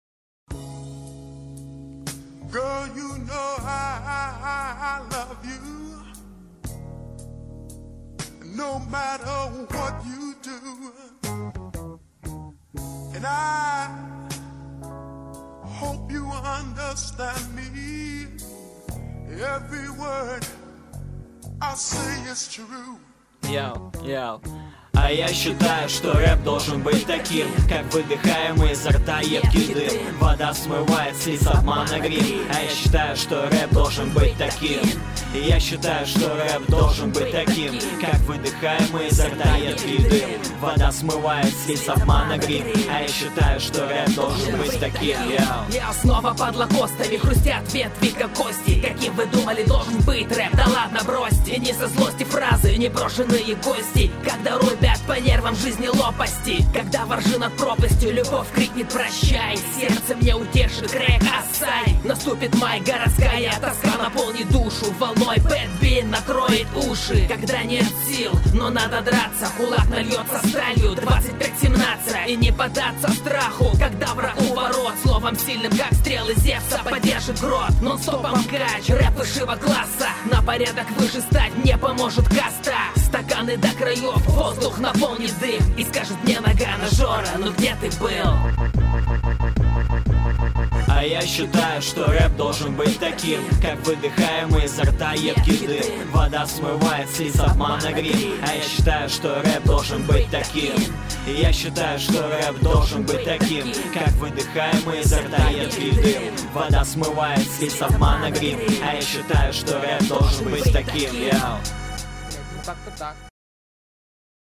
РЕП должен быть таким:
и это верзо.Поцы хотели прочитать рэп,а получился как всегда шансон.